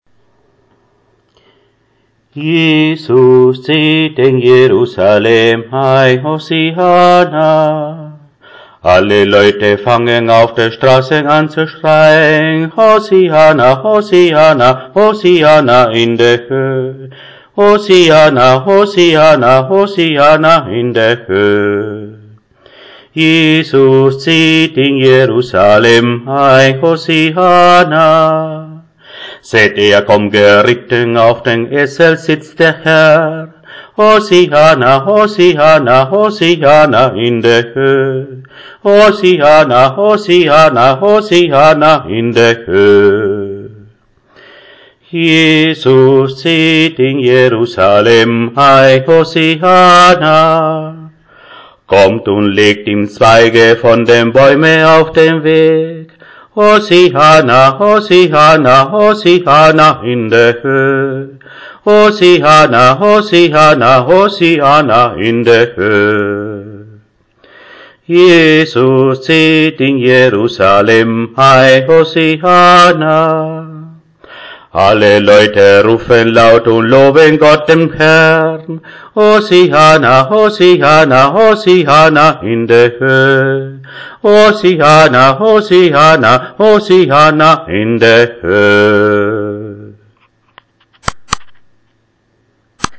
Palmsonntag
Palmsonntag.mp3